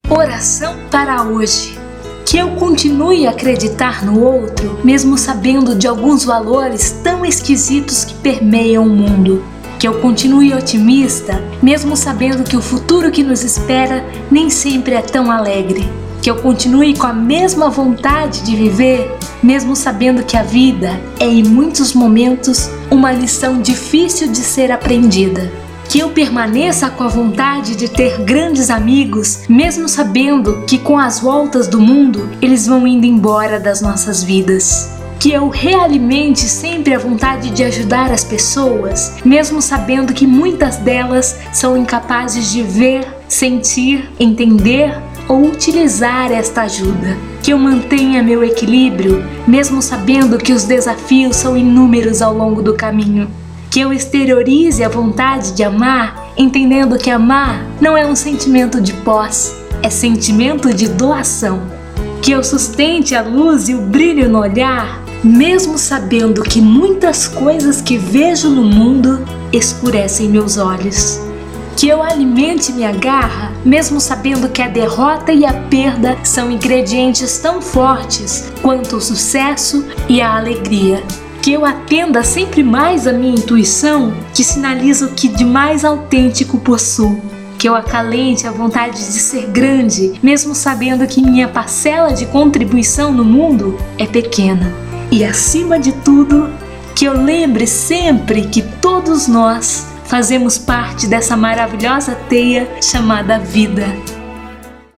Oração para hoje